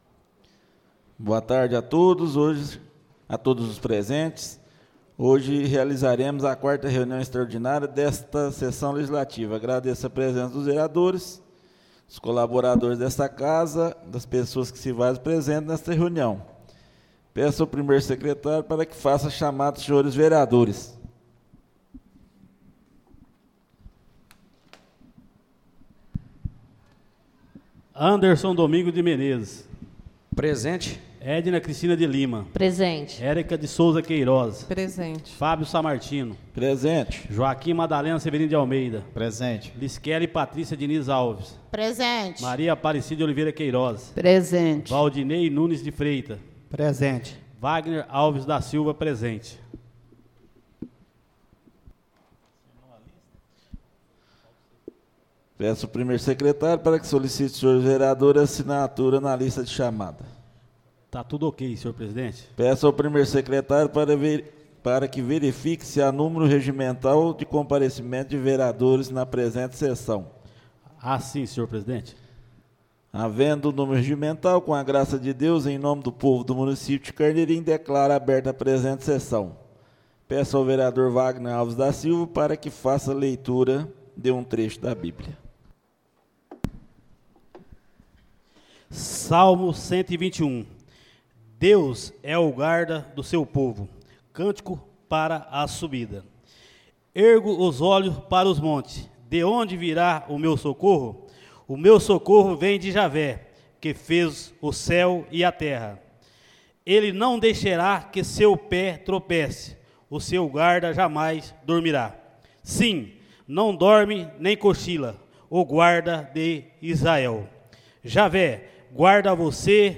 Áudio da 04.ª reunião extraordinária de 2025, realizada no dia 24 de Março de 2025, na sala de sessões da Câmara Municipal de Carneirinho, Estado de Minas Gerais.